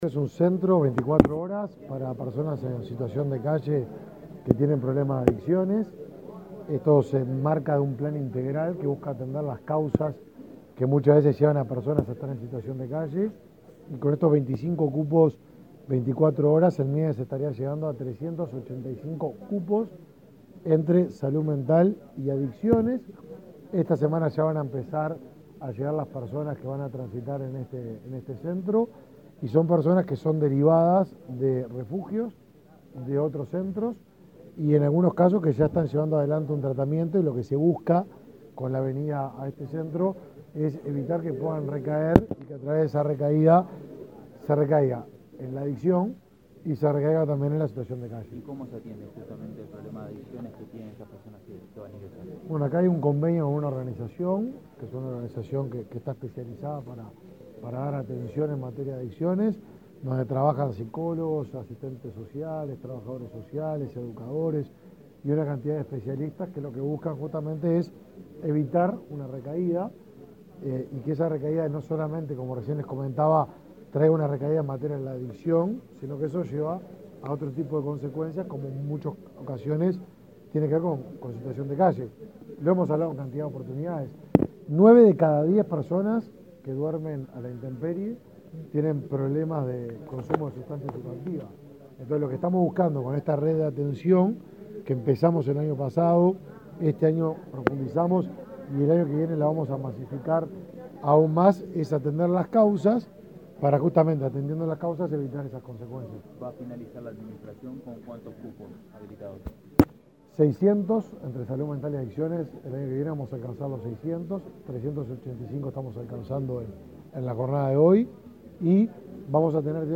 Declaraciones del ministro de Desarrollo Social, Martín Lema
Declaraciones del ministro de Desarrollo Social, Martín Lema 31/07/2023 Compartir Facebook X Copiar enlace WhatsApp LinkedIn Este lunes 31, el ministro de Desarrollo Social, Martín Lema, dialogó con la prensa luego de inaugurar en Montevideo una casa de medio camino para personas que consumen drogas de forma problemática.